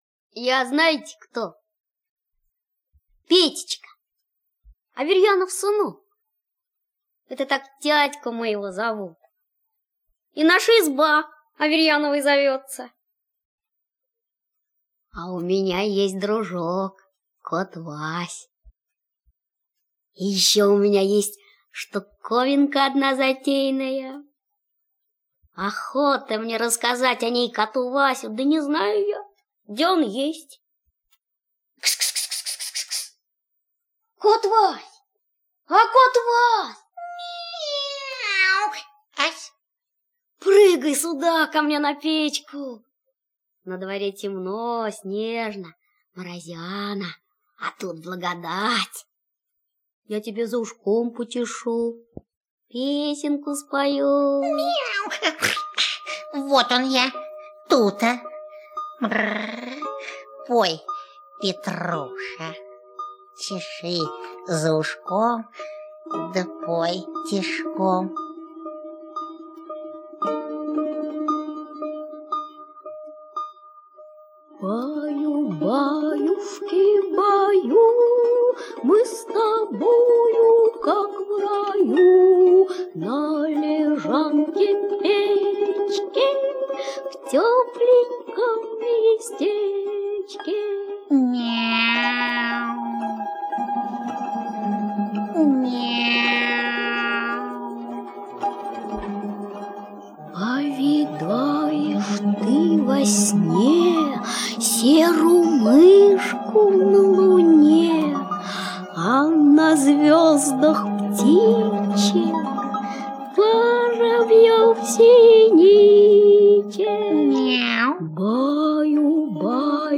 Снежный дом - аудиосказка Алексея Толстого - слушать онлайн